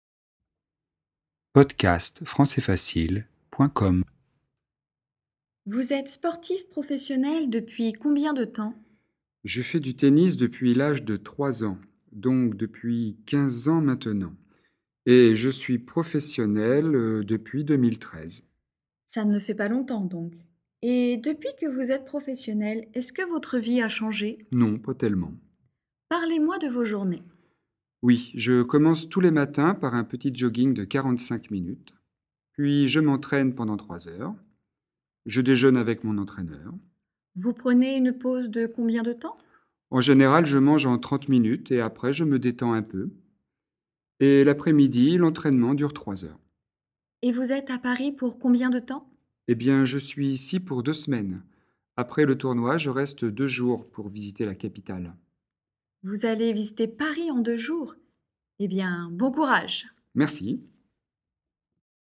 Dialogue FLE et exercice de compréhension, niveau intermédiaire (A2) sur le thème expression de la durée au présent
Situation : une journaliste interroge un joueur de tennis professionnel de passage à Paris.